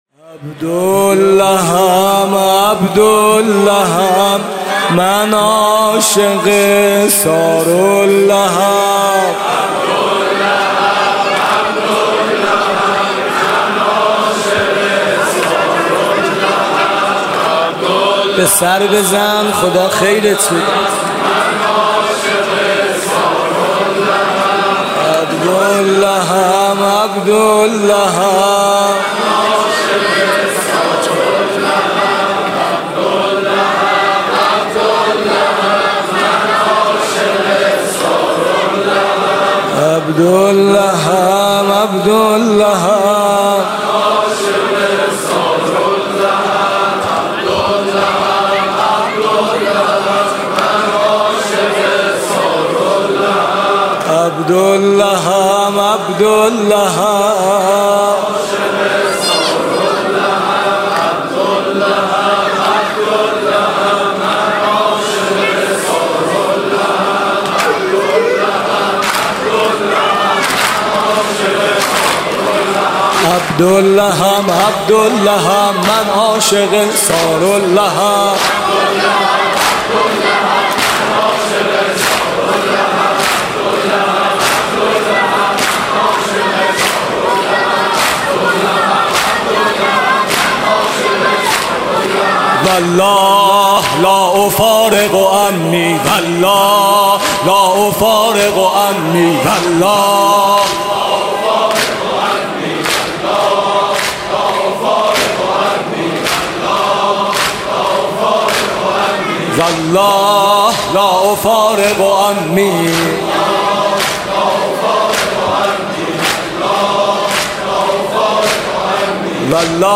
«محرم 1396» (شب پنجم) دودمه: عبدالله ام عبدالله ام من عاشق ثارالله ام